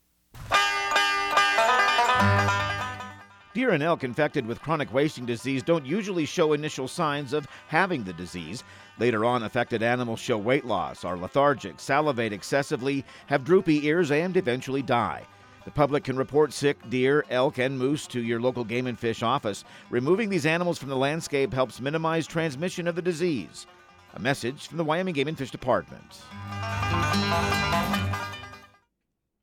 Outdoor Tip-PSA